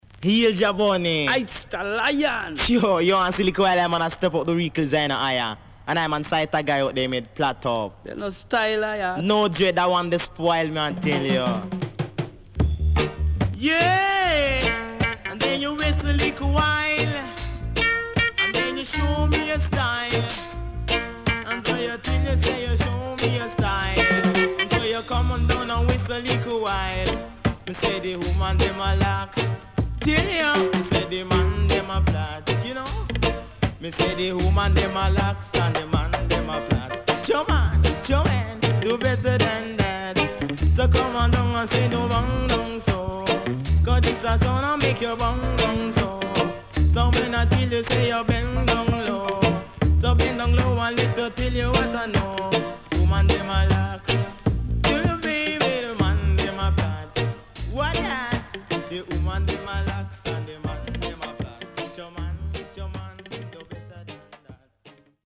ファルセットが最高!
SIDE A:序盤少しノイズ入ります。